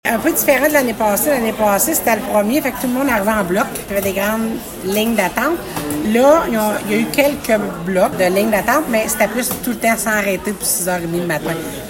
La préfète de la Vallée-de-la-Gatineau, Chantal Lamarche, en dit davantage :